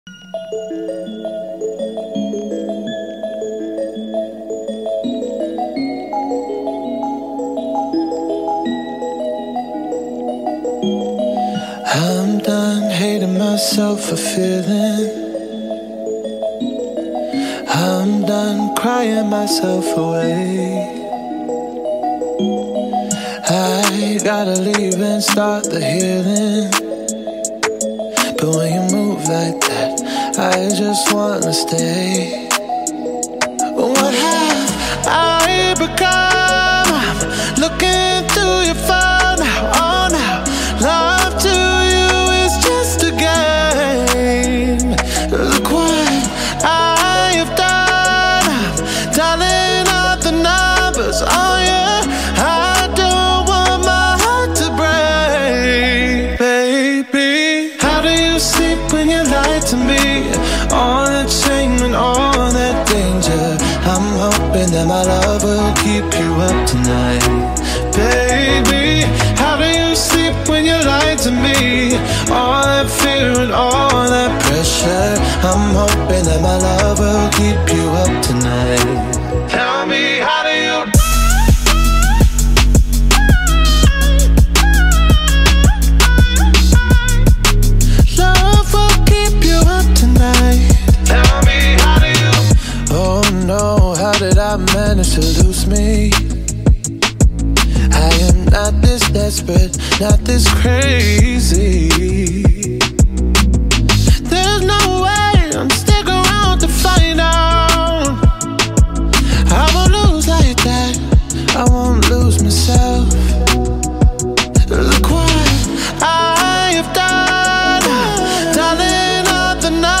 R&B song